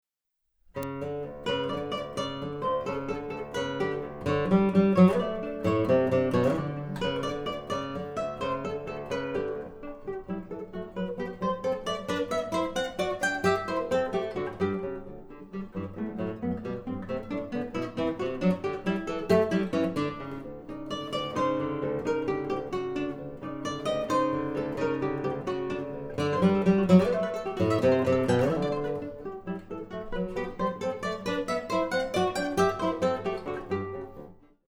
Luthier Wood Samples